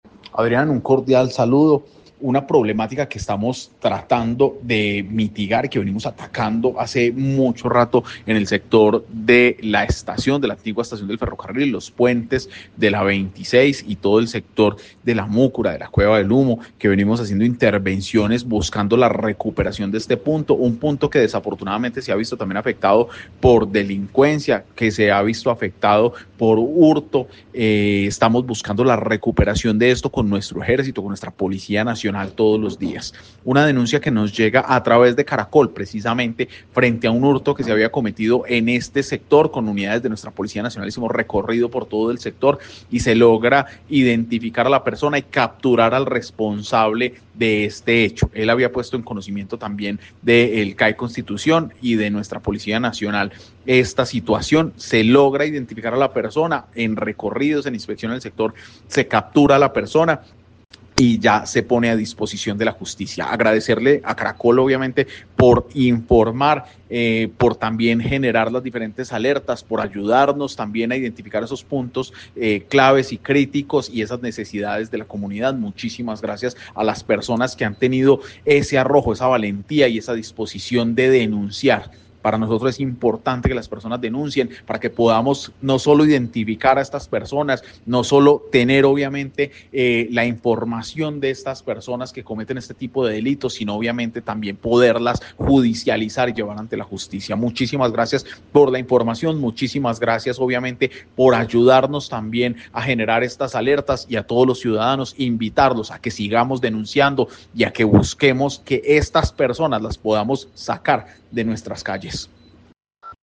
Secretario de gobierno de Armenia, Andrés Buitrago